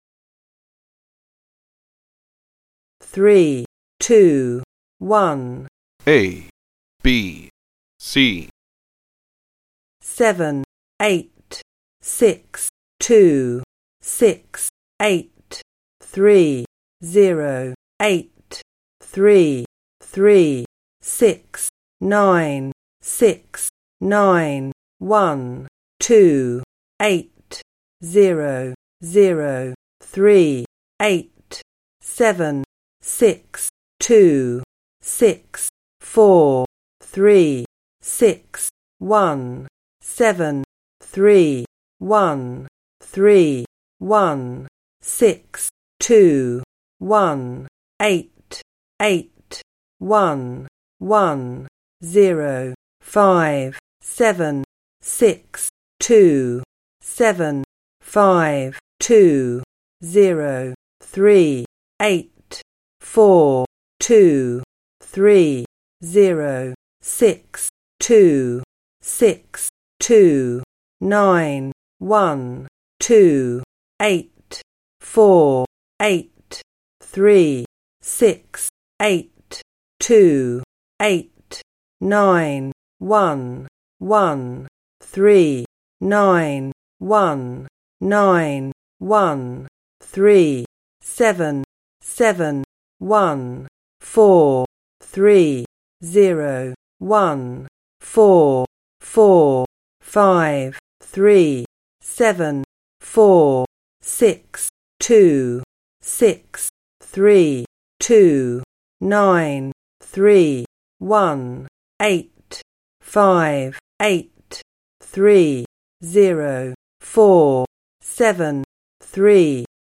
11 300 Spoken Numbers